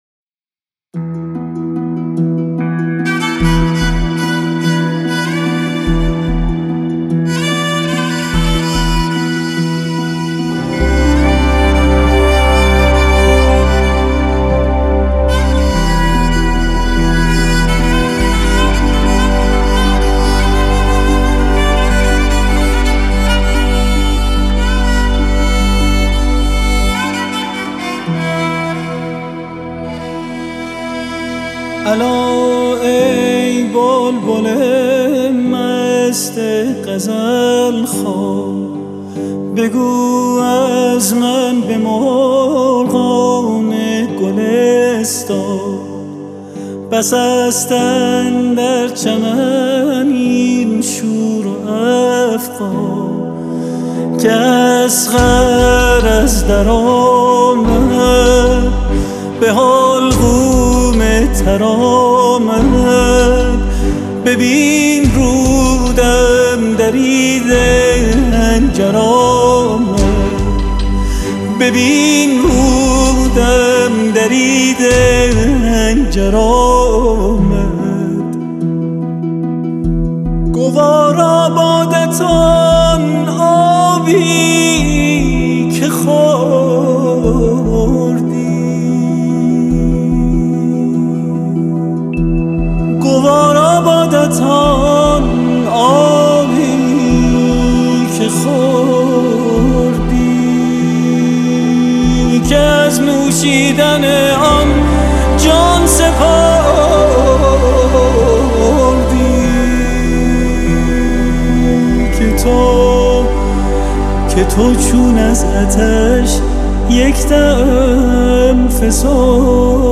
این قطعه در آواز دشتی